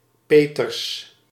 Peeters (Dutch pronunciation: [ˈpeːtərs]